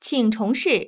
ivr-please_try_again.wav